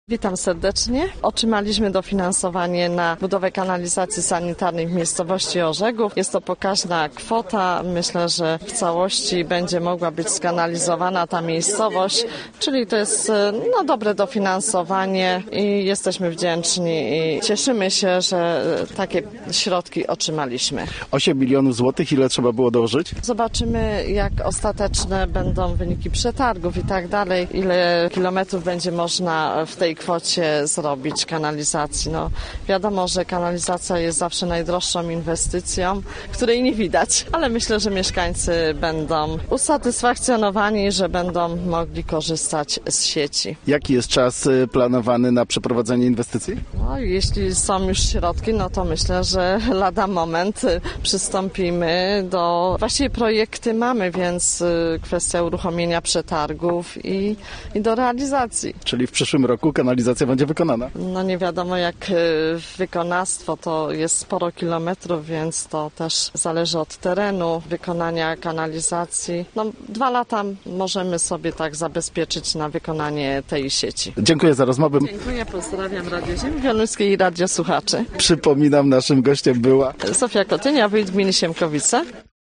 Gościem Radia ZW była Zofia Kotynia, wójt gminy Siemkowice